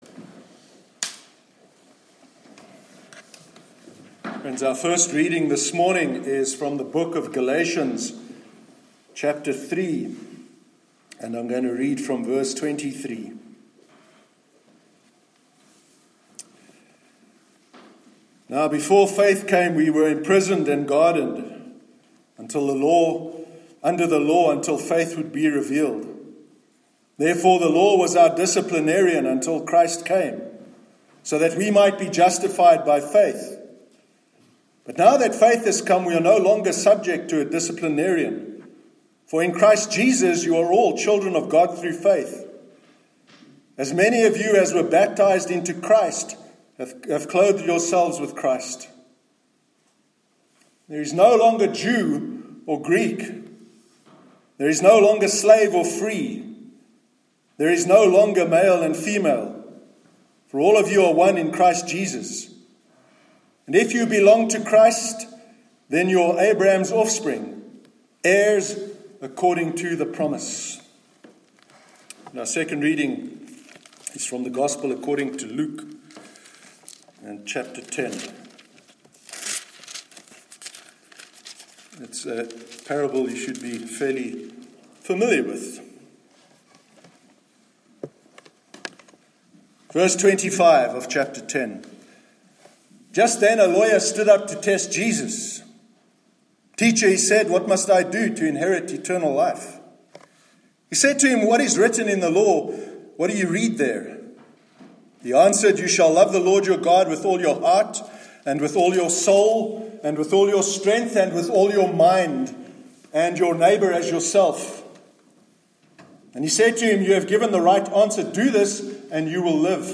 Sermon on Tribalism- 29th July 2018